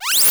open_003.ogg